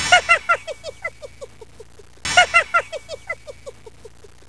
Tickle Elmo and hear him laugh!
elmolaugh.wav